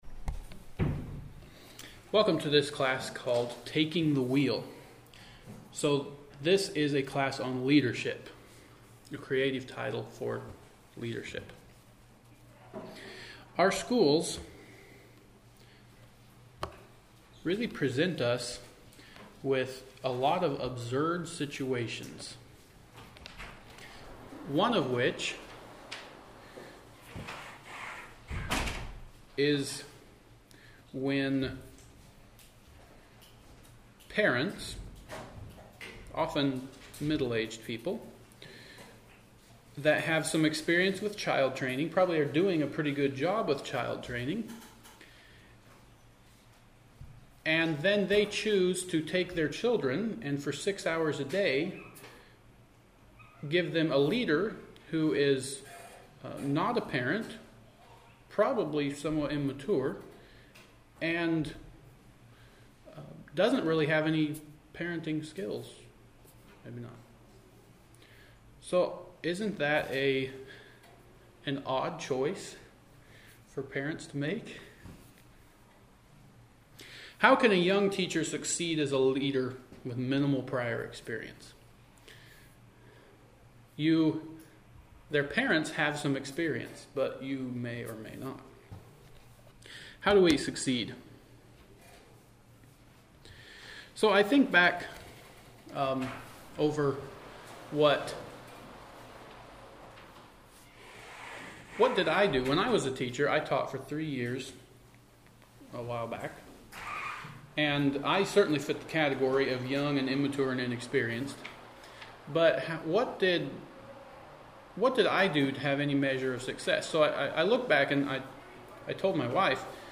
2025 Western Fellowship Teachers’ Institute 2018 Taking the Wheel Audio 00:00